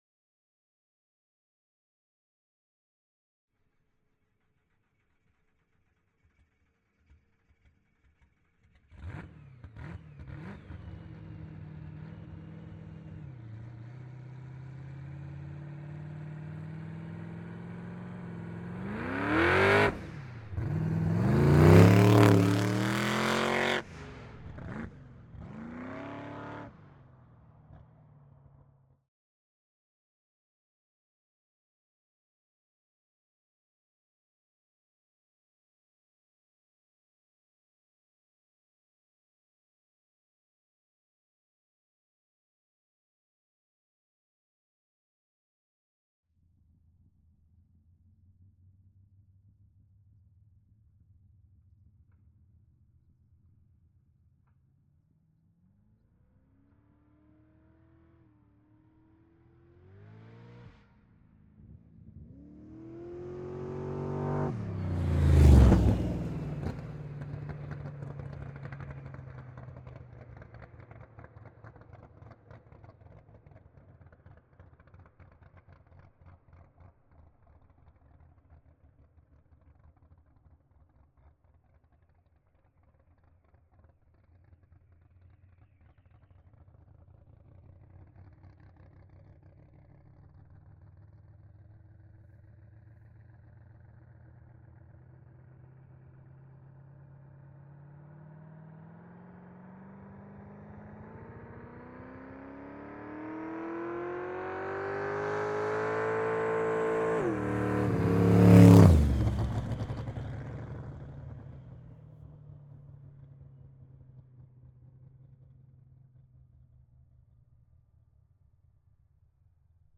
Pole Position - Volvo 142 Drag Car